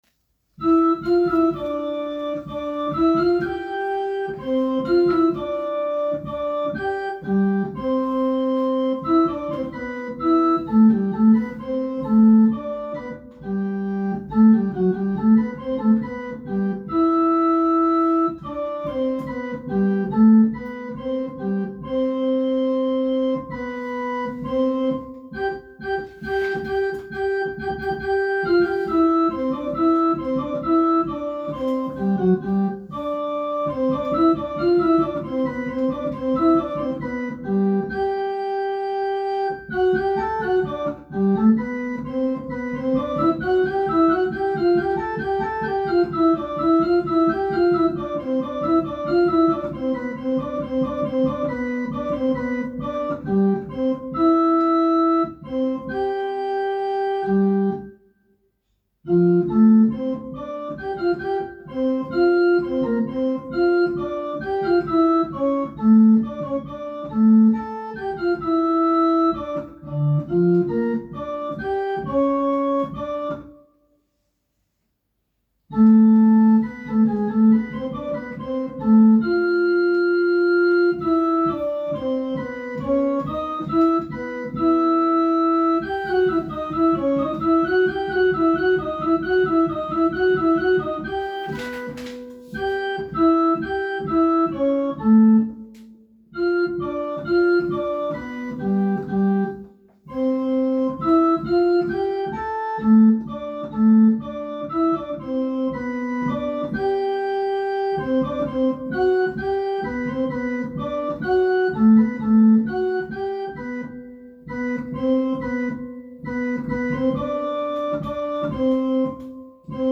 Tenor_Jauchzet.m4a